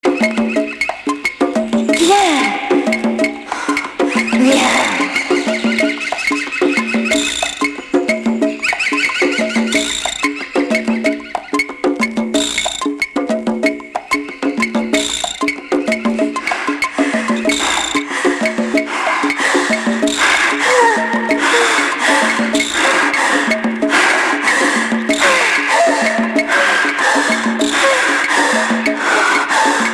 Erotico groove percus